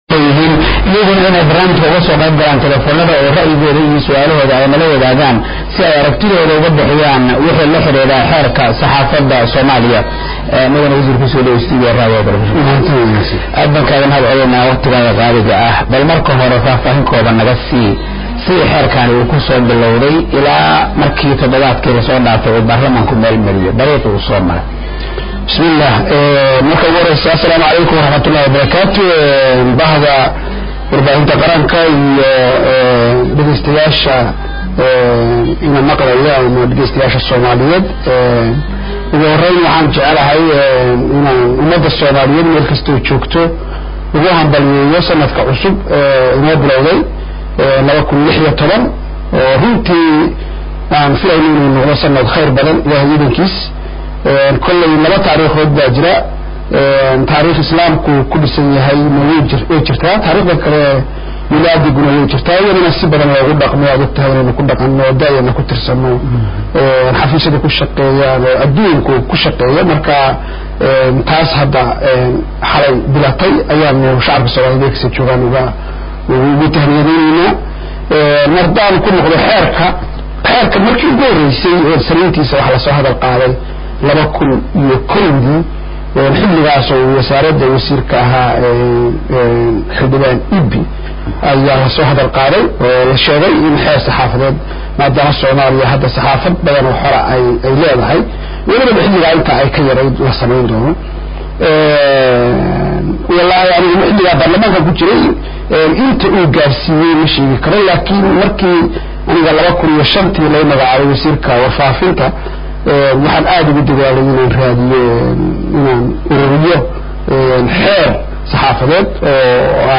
Wasiirka warfaafinta, dhaqanka iyo dalxiiska ee xukuumadda Soomaaliya Maxamed Cabdi Xayir “Maareeye” oo maanta marti ku ahaa barnaamijka kulanka todobaadka